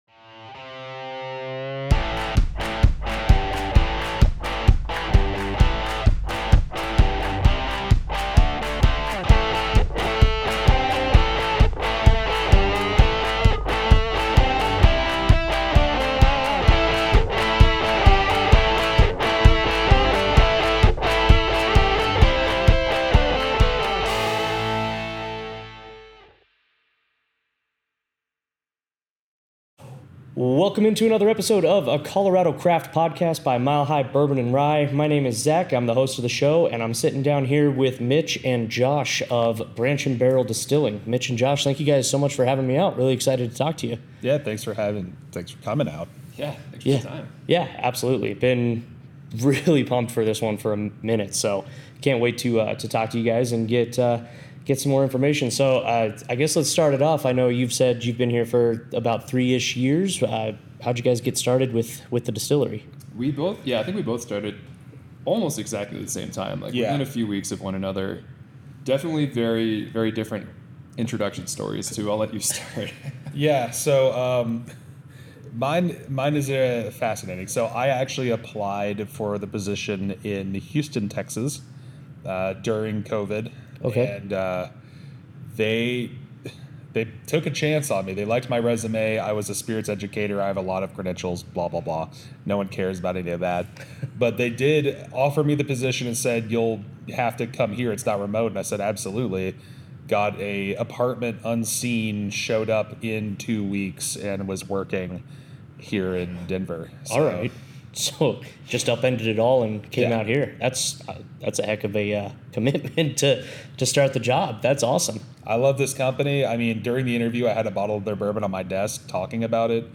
Join me at the Branch and Barrel Tasting room and distillery